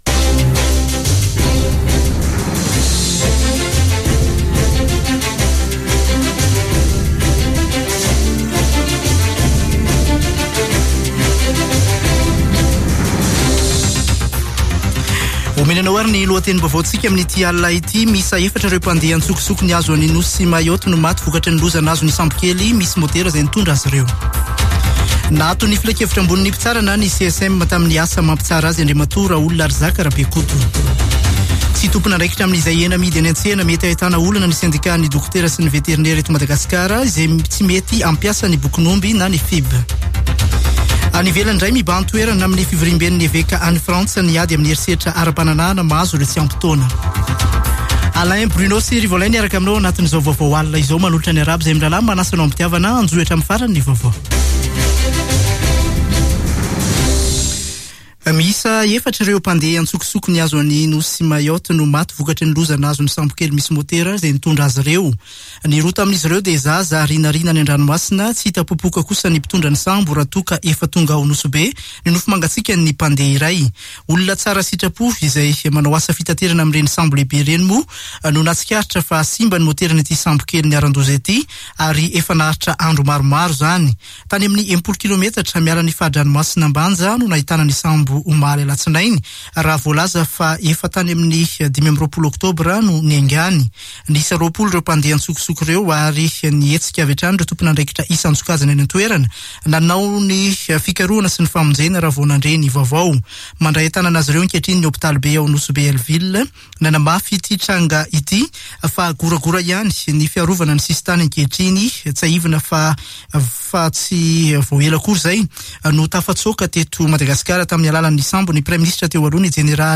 [Vaovao hariva] Talata 2 novambra 2021